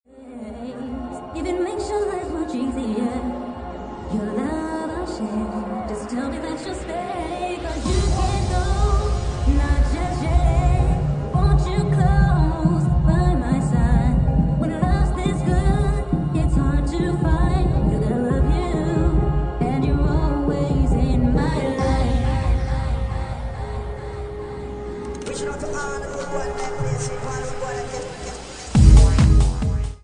69 bpm